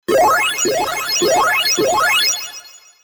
8ビットの爆風音。懐かしいゲームサウンド。8ビット時代のゲームを彷彿とさせる、懐かしくも臨場感あふれる爆風効果音です。